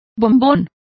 Complete with pronunciation of the translation of chocolate.